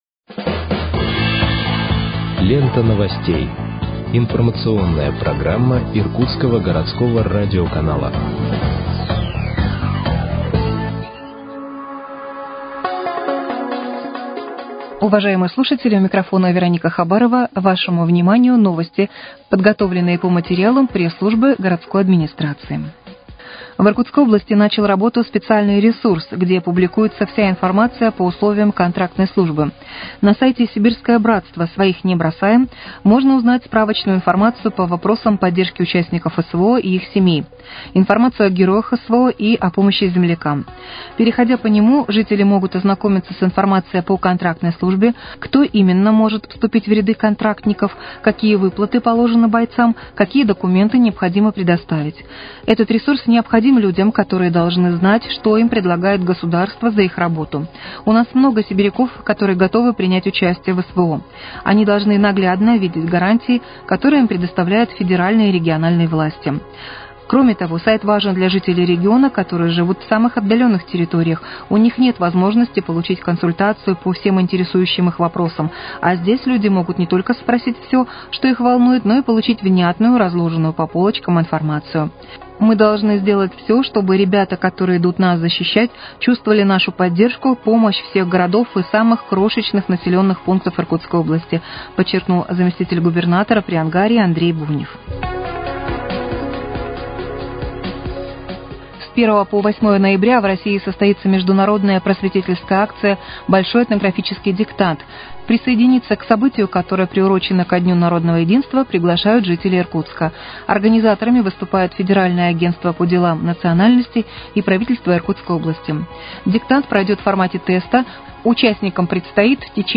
Выпуск новостей в подкастах газеты «Иркутск» от 28.10.2024 № 2